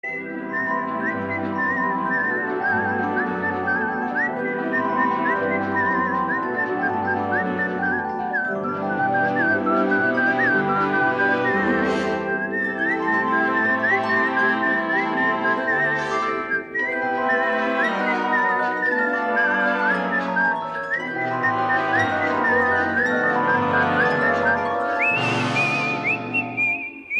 électronique